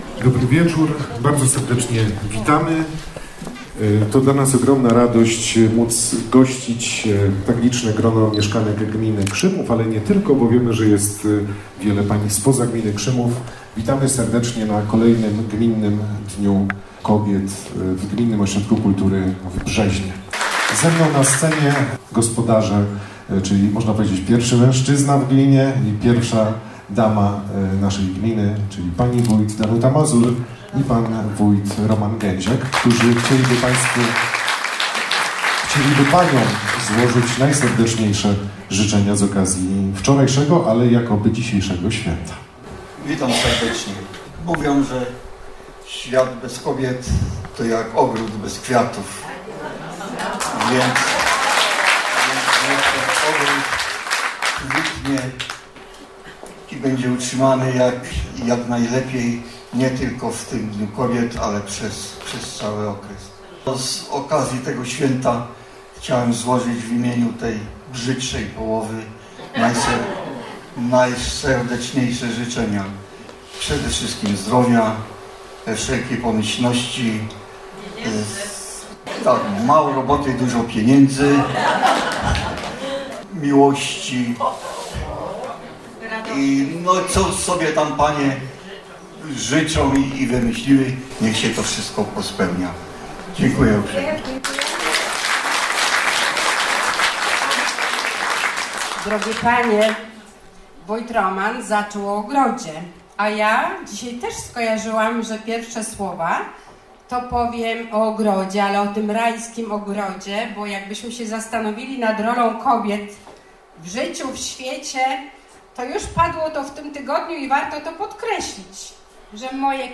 Artysta wystąpił dziewiątego marca w sali widowiskowej Gminnego Ośrodka Kultury w Brzeźnie.
Przed rozpoczęciem występu, Roman Gęziak, Zastępca Wójt Gminy Krzymów oraz wójt Danuta Mazur złożyli życzenia wszystkim paniom obecnym na tym wydarzeniu.
Publiczność, zwłaszcza panie, które licznie przybyły na koncert, żywo reagowała na każdą piosenkę i chętnie włączała się do interakcji.
Emocje sięgnęły zenitu, gdy po wykonaniu ostatniej piosenki, artysta został wywołany na scenę przez owacje na stojąco.